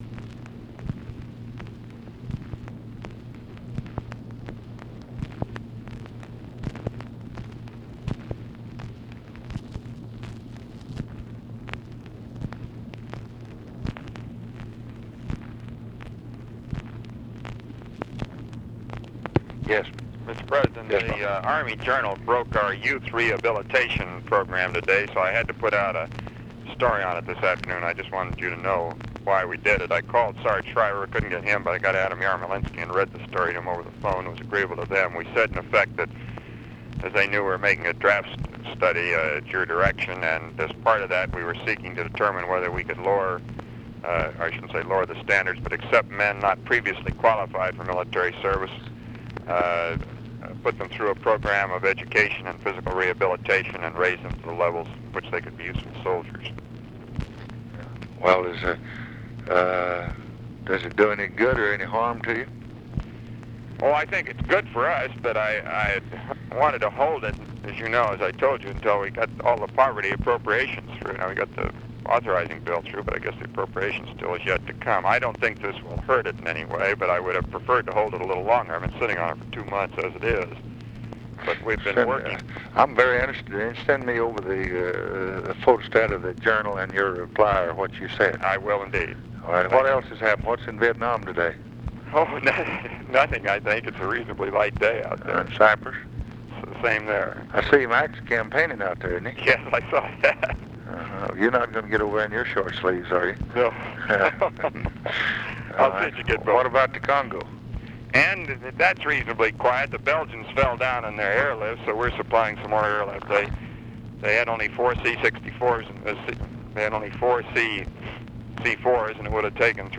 Conversation with ROBERT MCNAMARA, August 13, 1964
Secret White House Tapes